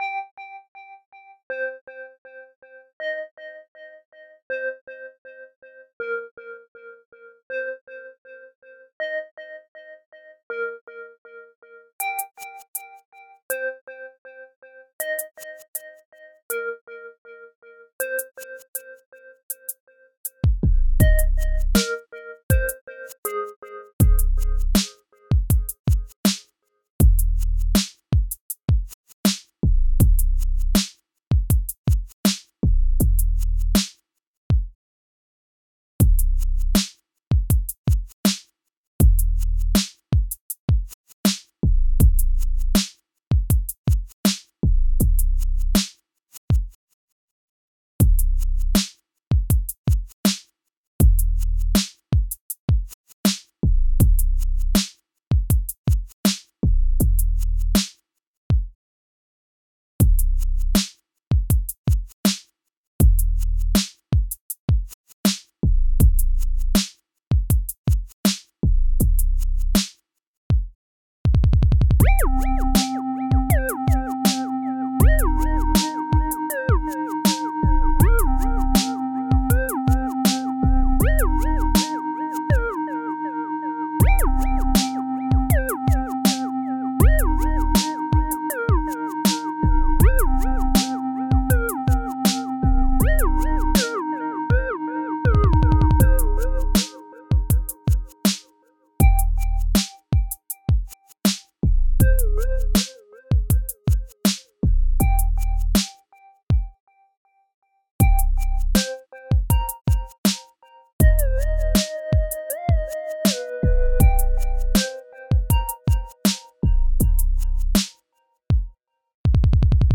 The effect on this beat came about by a complete accident. While attempting to move a note I unintentionally created a slide effect that sounded interesting.